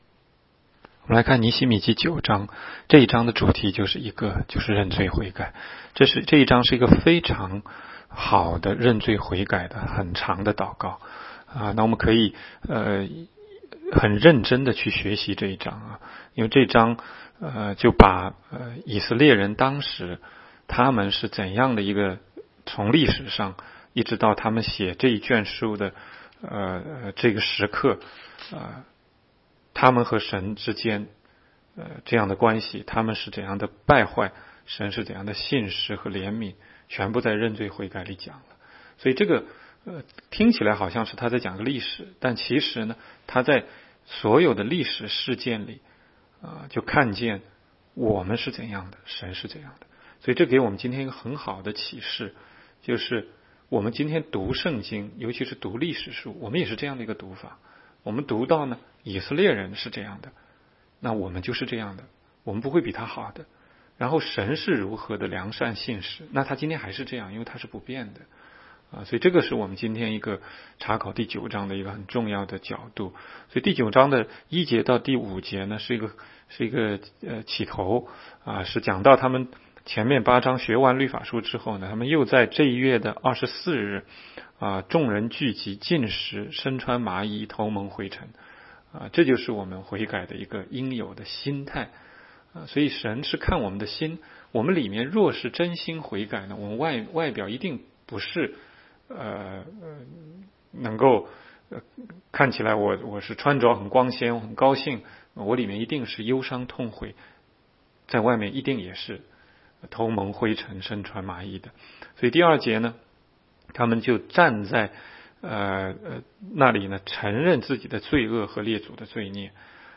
16街讲道录音 - 每日读经-《尼希米记》9章